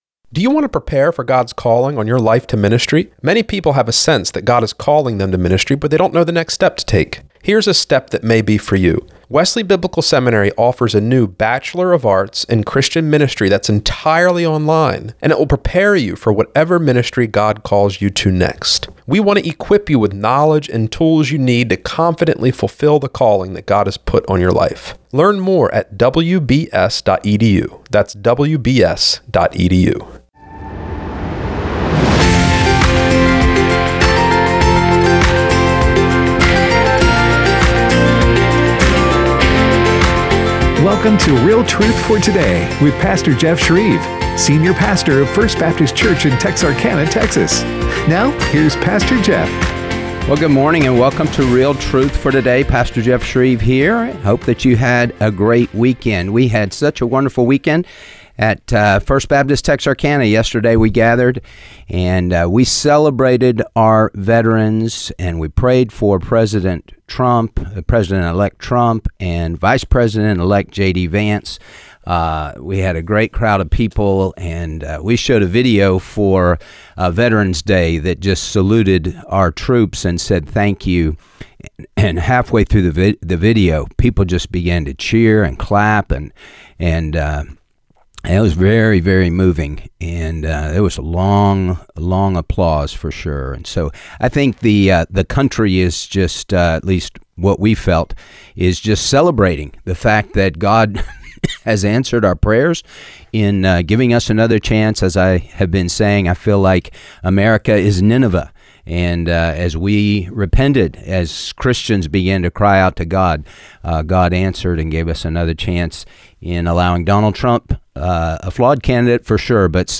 Post Election Discussion